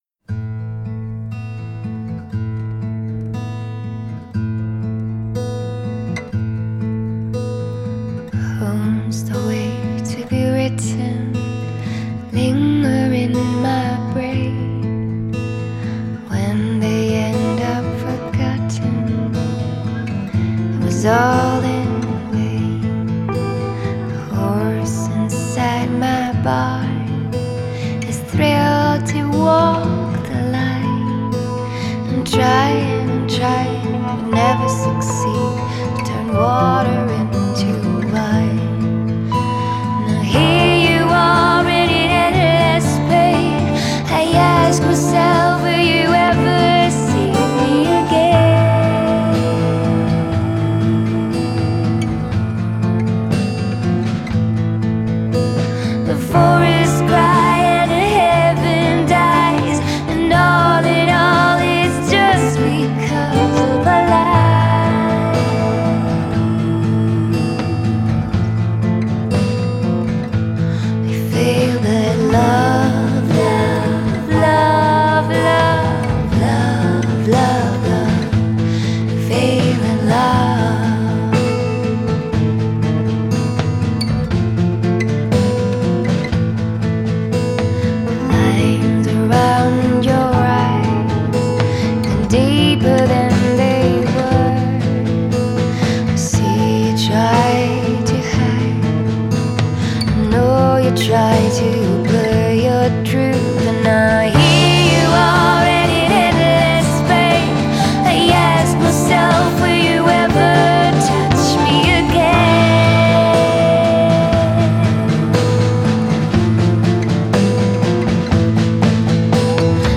Genre: Indie Pop